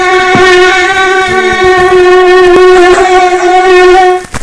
Mosquito 66 Sound Effect Free Download